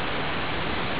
Play Triangle wave sound This is very close to a sine wave (which contains only the fundamental frequency and no harmonics). It is a very 'pure' sounding waveform, producing a flute like sound.